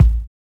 Wu-RZA-Kick 1.wav